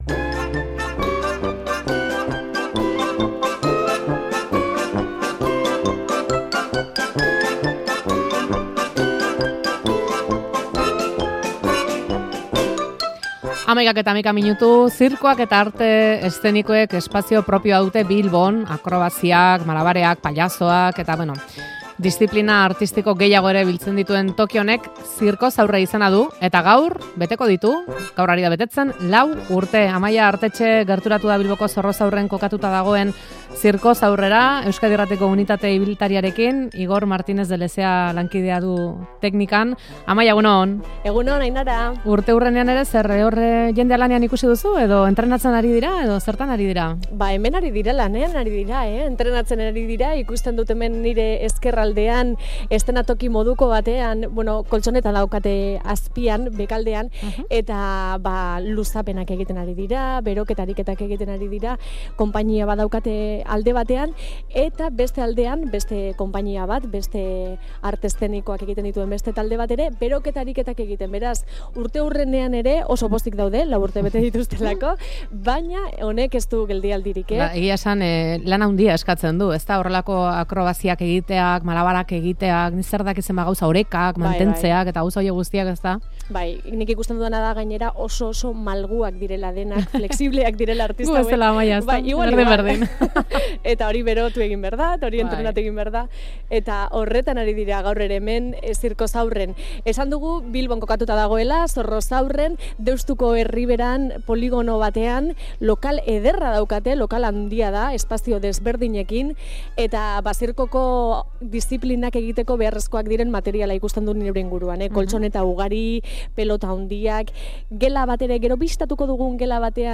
Zirkozaurren izan da Faktoria; zirkuaren eta arte eszenikoen etxean.